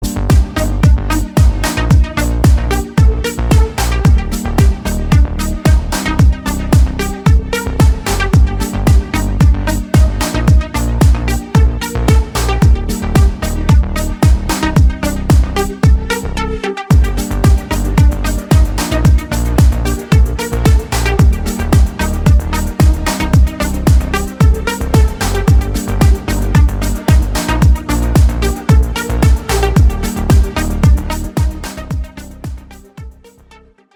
• Качество: 320, Stereo
deep house
атмосферные
без слов
Electronica